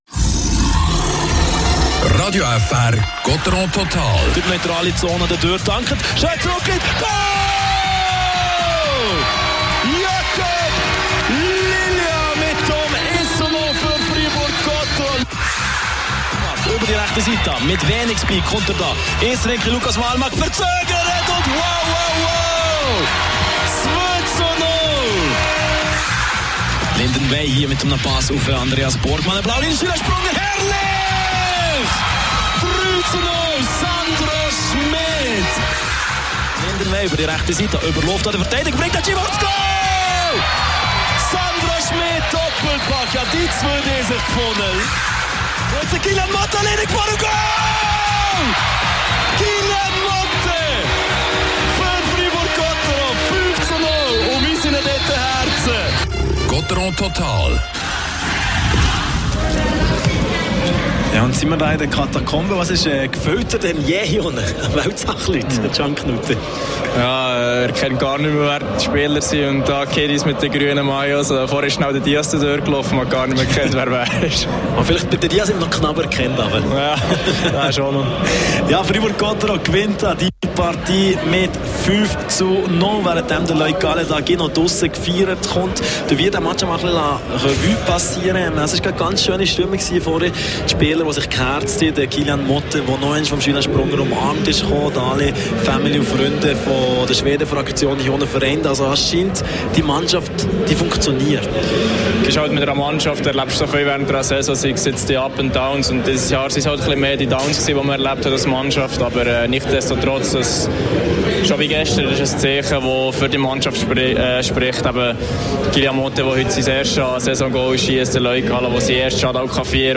und das Interview mit Raphael Diaz gibts im Podcast.